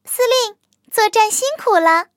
卡尔臼炮战斗返回语音.OGG